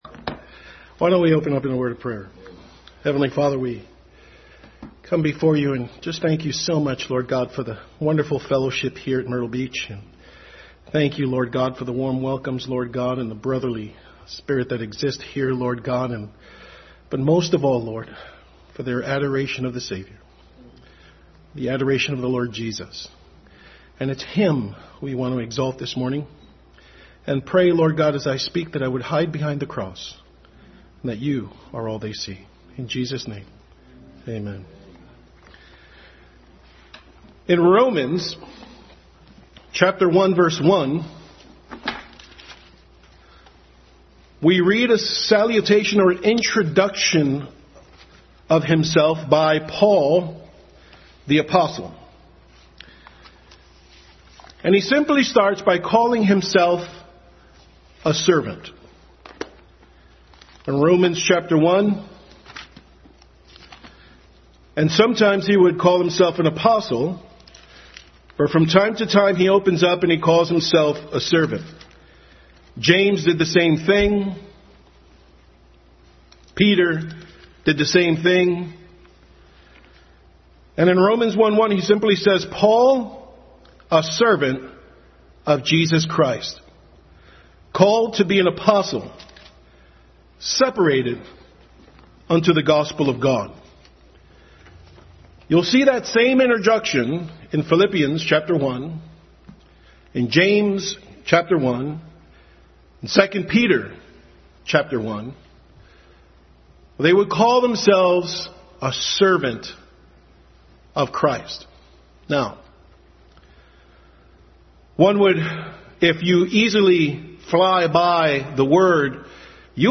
Adult Sunday School message.